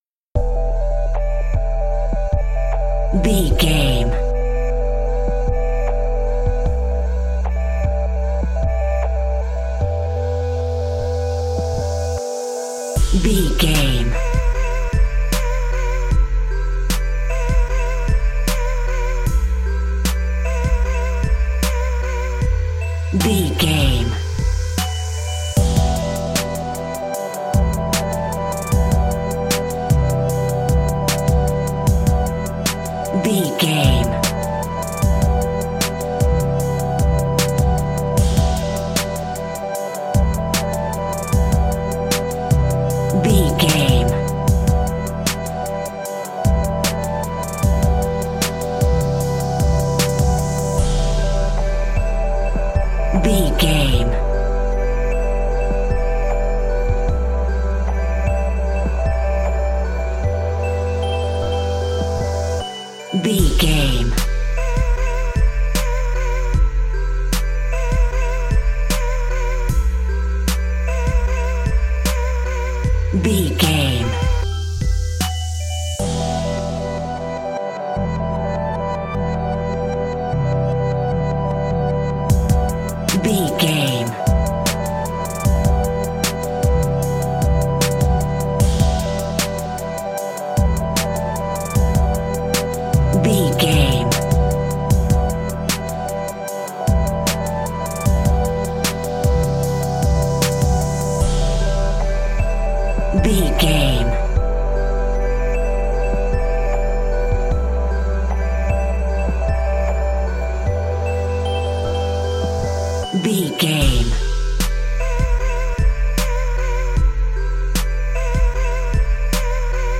Aeolian/Minor
instrumentals
chilled
laid back
groove
hip hop drums
hip hop synths
piano
hip hop pads